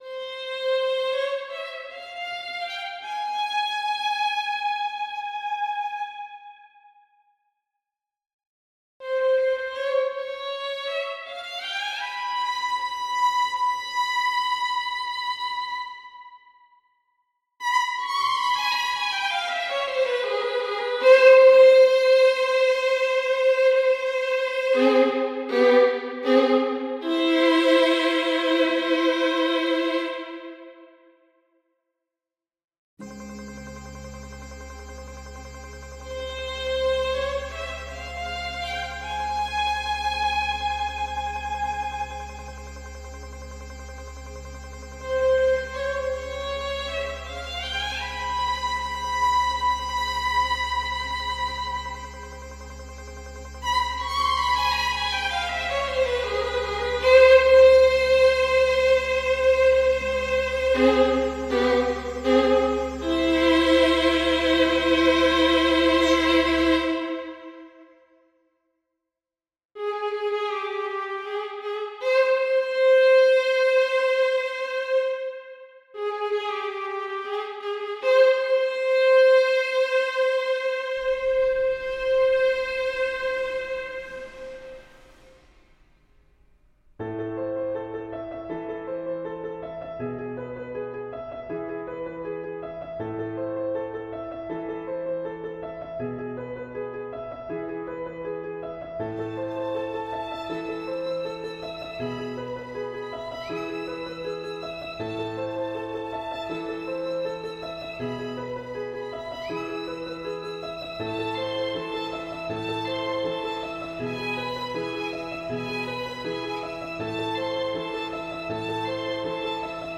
Per Orchestra
Piccolo
2 Flute
2 Oboe
English Horn
2 Clarinet in Bb
Bass Clarinet
2 Bassoon
Contrabassoon
4 Horn in F
3 Trumpet in C
2 Tenor Trombone
1 Bass Trombone
Timpani
Triangle
Suspended Cymbal
Snare Drum
Sitar
Harp
Piano
Violin I (16)
Violin II (14)
Viola (12)
Violoncello (10)
Contrabass (8)